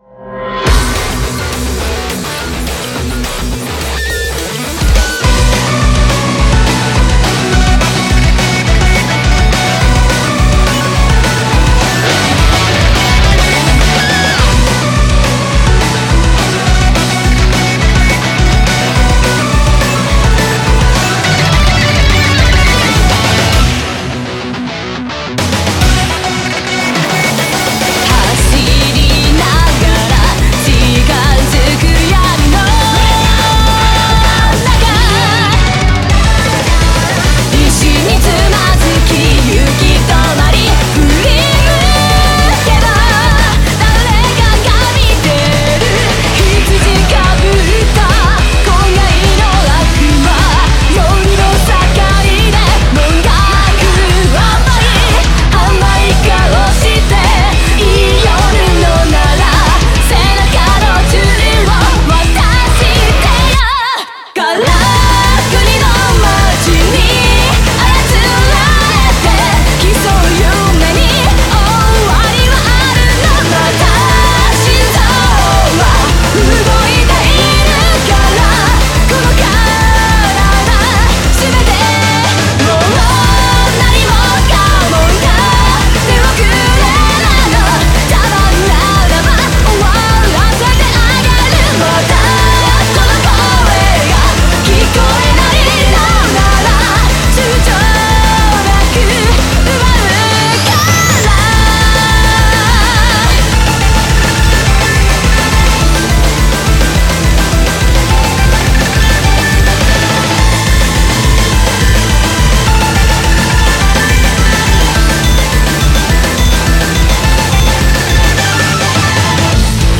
BPM210
Comments[J-METAL]